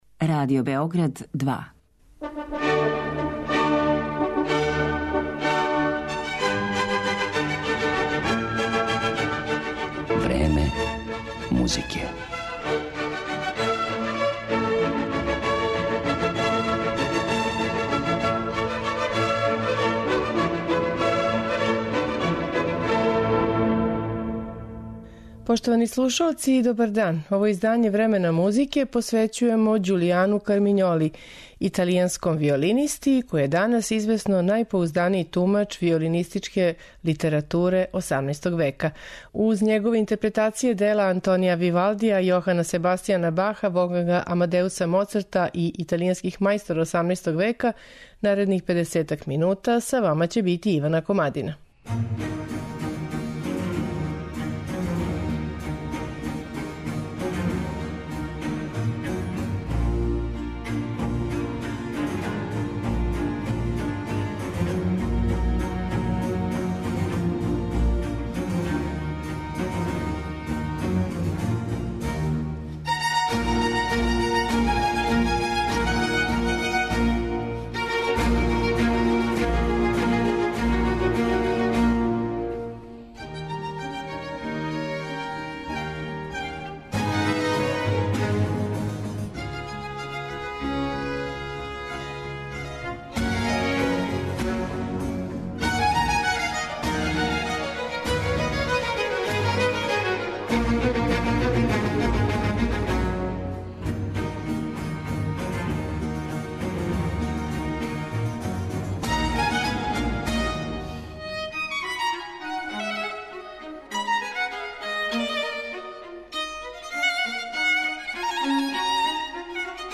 италијанског виолинисту